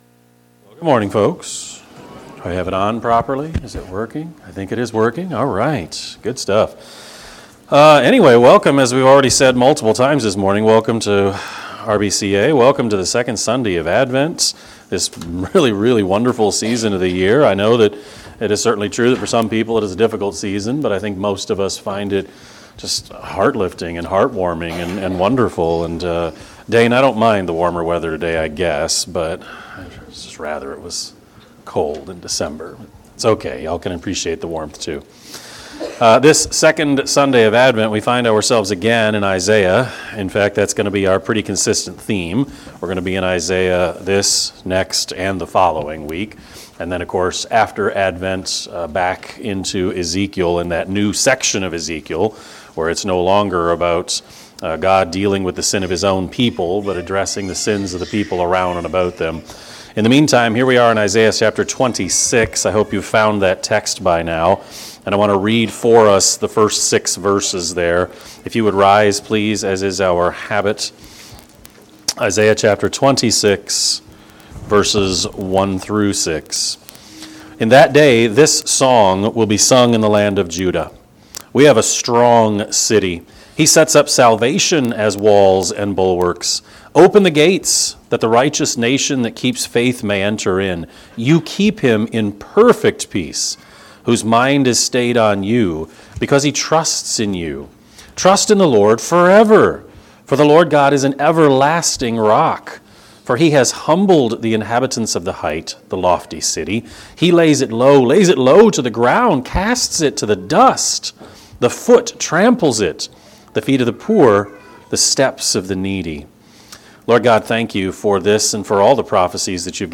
Sermon-12-8-24-Edit.mp3